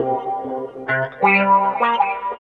110 GTR 2 -L.wav